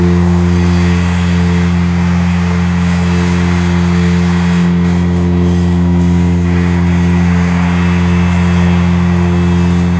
Звук фена для волос